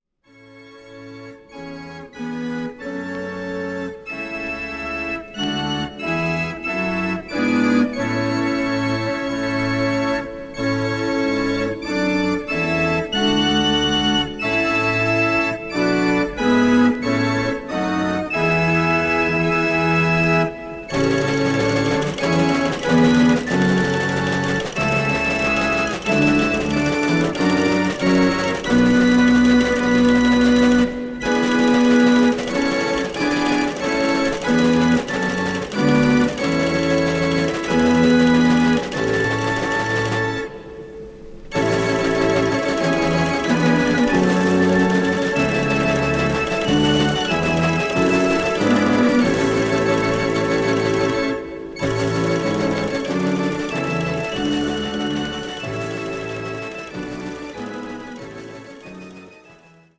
Formaat: Vinyl, LP, Stereo
Stijl: Organ